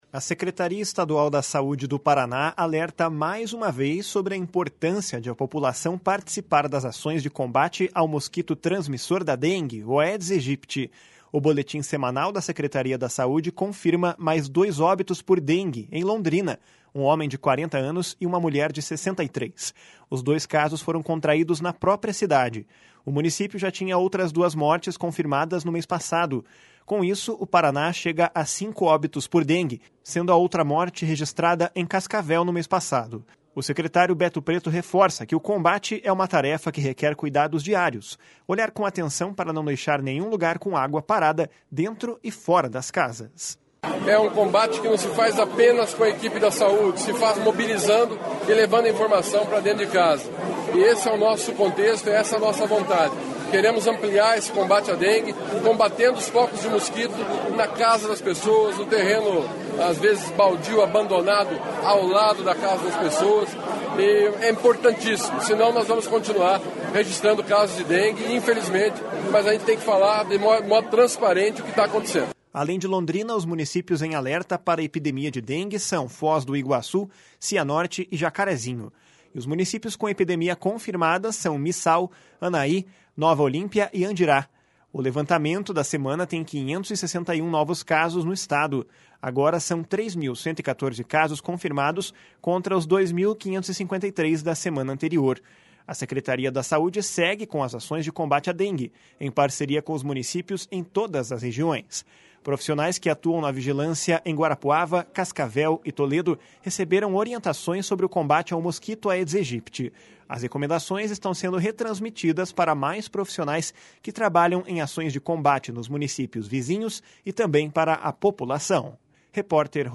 O secretário Beto Preto reforça que essa é uma tarefa que requer cuidados diários, olhar com atenção para não deixar nenhum lugar com água parada dentro e fora das casas. // SONORA BETO PRETO //